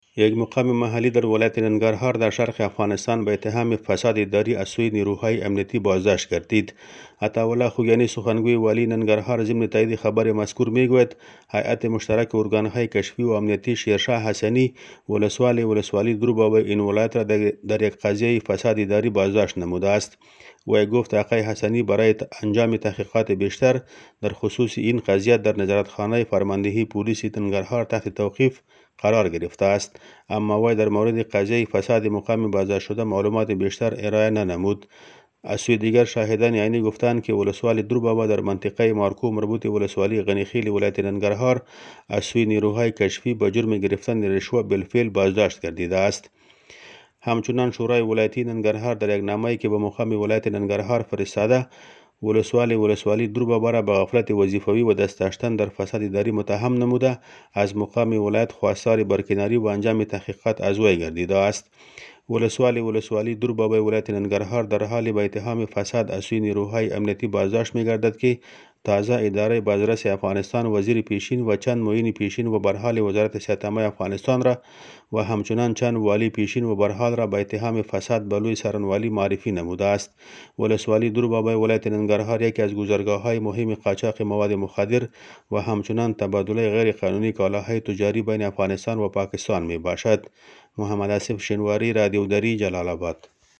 جزئیات بیشتر در گزارش